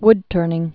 (wdtûrnĭng)